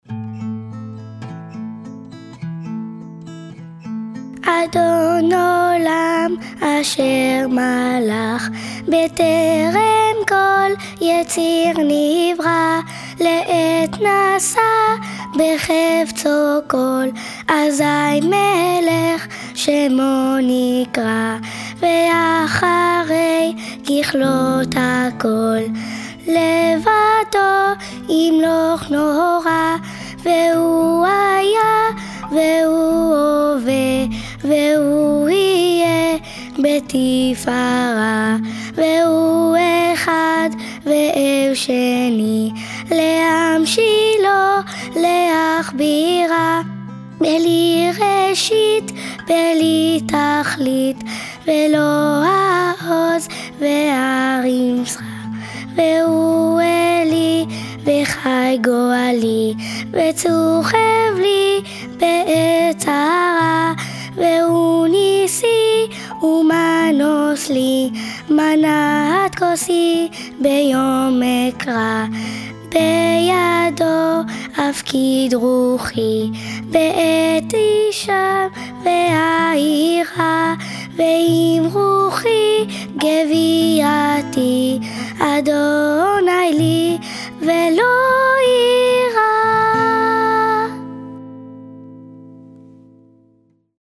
« Adon Olam » est un poème liturgique chanté à l’office du matin.
Audio Enfants: